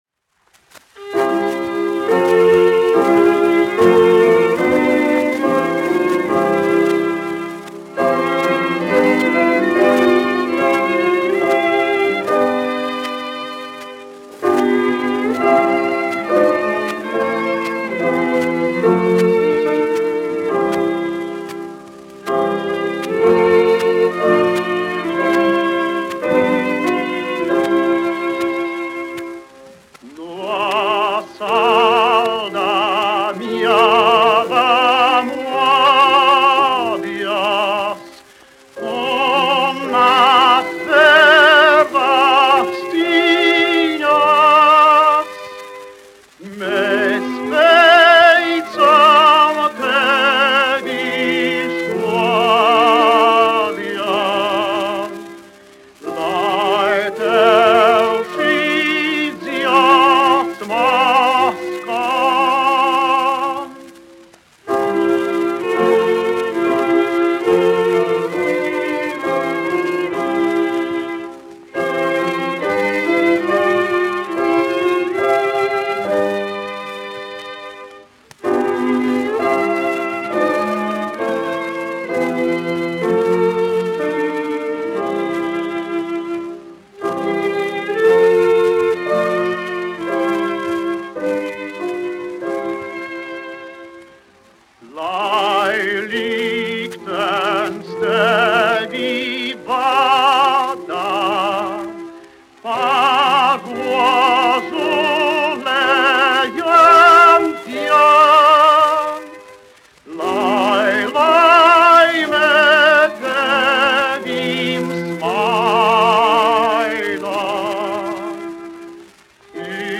No salda miega modies : koralis
1 skpl. : analogs, 78 apgr/min, mono ; 25 cm
Garīgās dziesmas
Latvijas vēsturiskie šellaka skaņuplašu ieraksti (Kolekcija)